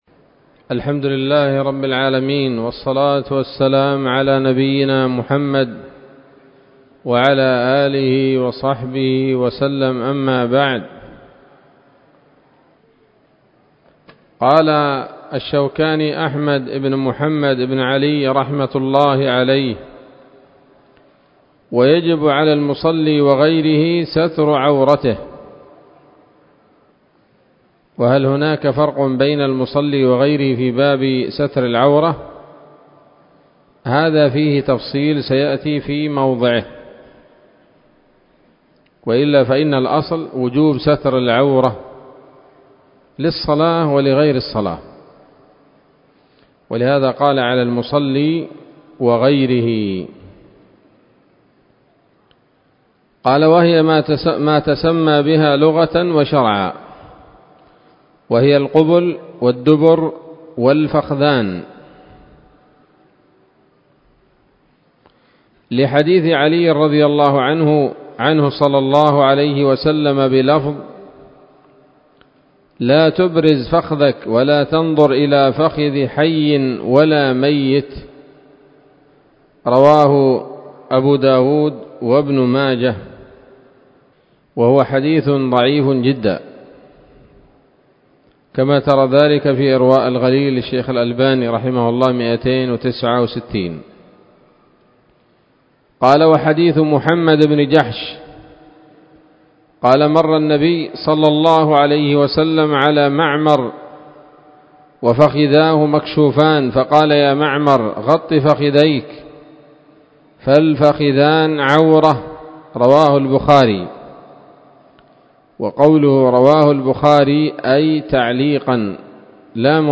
الدرس التاسع من كتاب الصلاة من السموط الذهبية الحاوية للدرر البهية